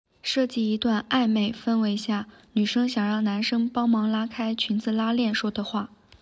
speech generation